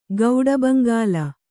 ♪ gauḍa baŋgāla